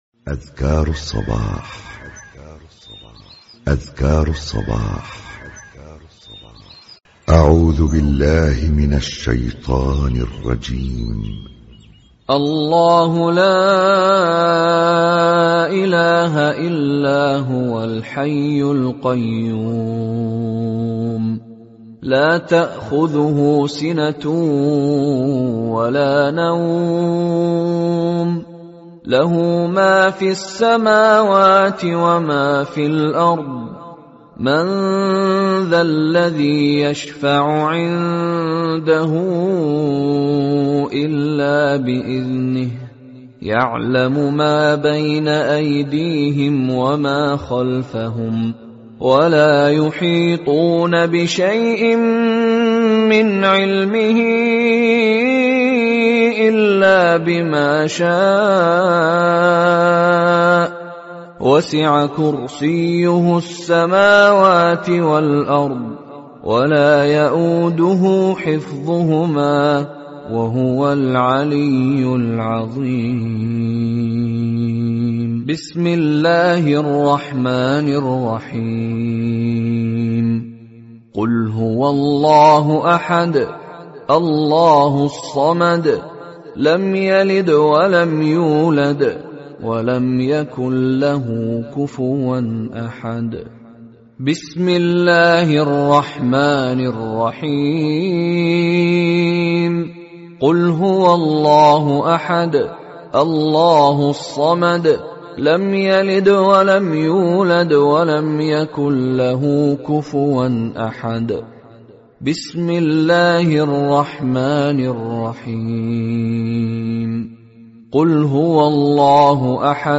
Rekaman Dzikir Pagi